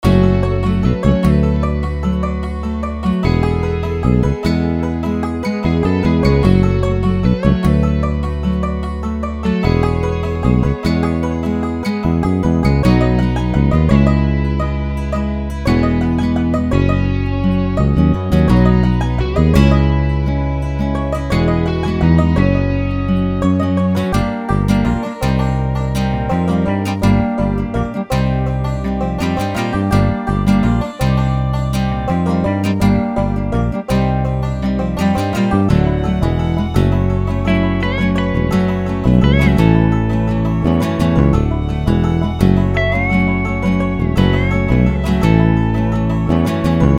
这些样本不包括鼓，但它们确实包括充满灵感和国家能量的样本。